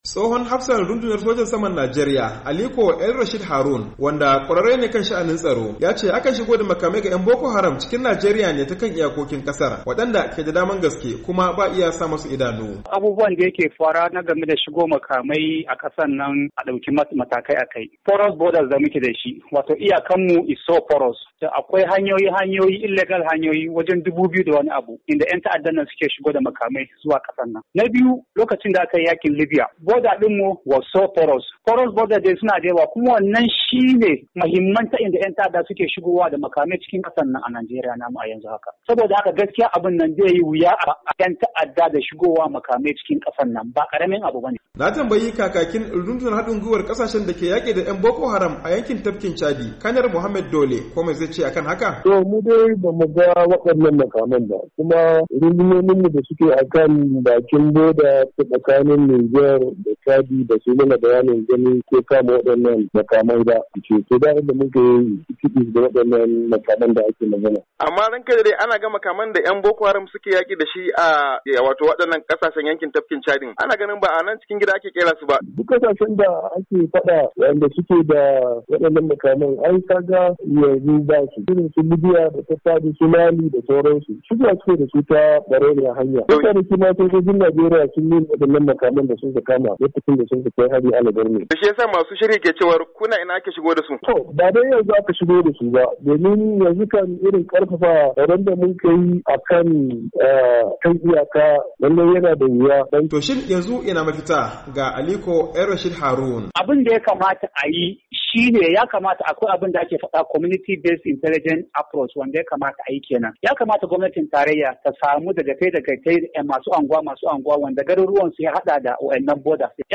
A lokacin da yake zantawa da wakilin Muryar Amurka